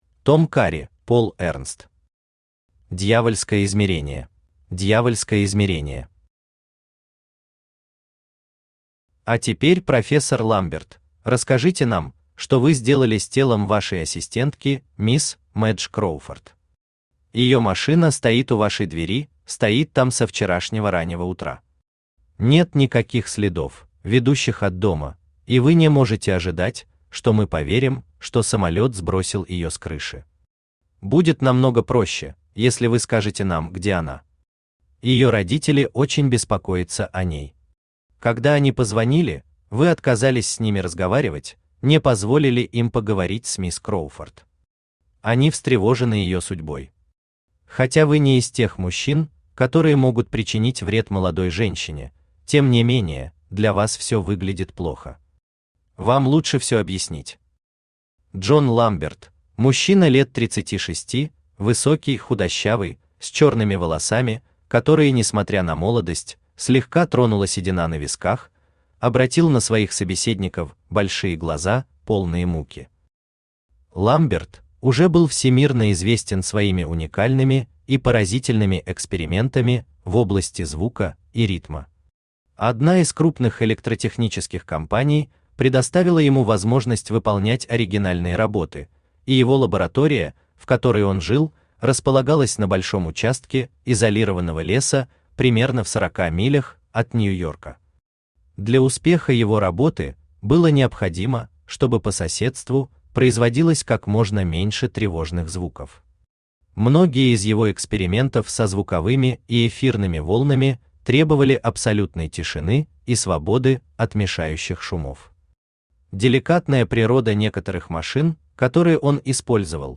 Аудиокнига Дьявольское измерение | Библиотека аудиокниг
Aудиокнига Дьявольское измерение Автор Том Карри Читает аудиокнигу Авточтец ЛитРес.